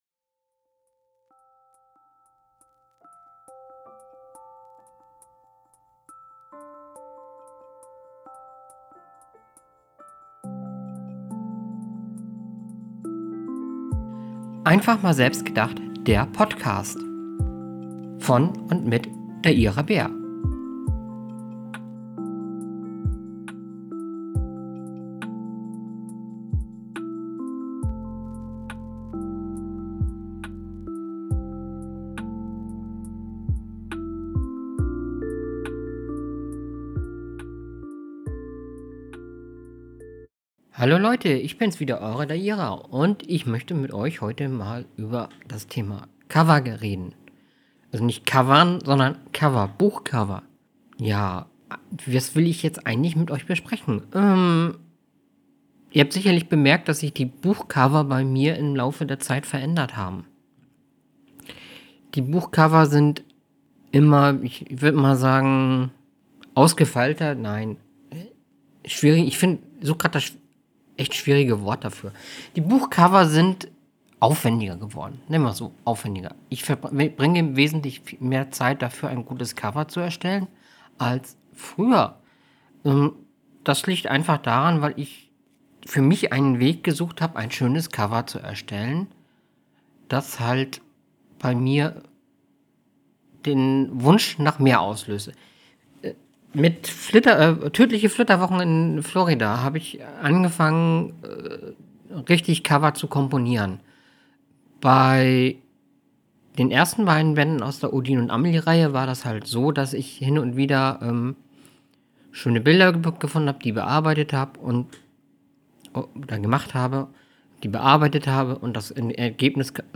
Heute möchte ich, mit Euch über das Thema Cover bei Büchern reden. Und erzählen, warum ich die Buchcover alle selber erstelle. Natürlich gibt es auch wieder eine kleine Leseprobe.